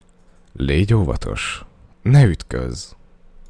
crashhun.mp3